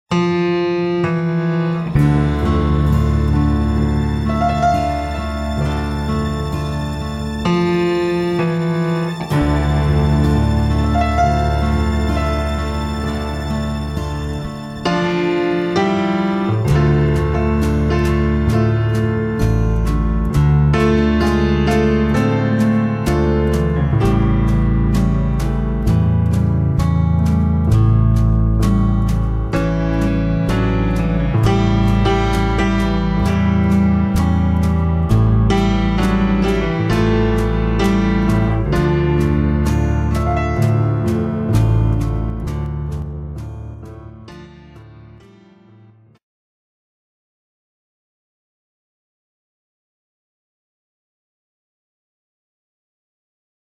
20 CLASSIC PIANO INSTRUMENTALS